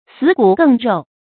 死骨更肉 sǐ gǔ gèng ròu
死骨更肉发音
成语注音 ㄙㄧˇ ㄍㄨˇ ㄍㄥ ㄖㄡˋ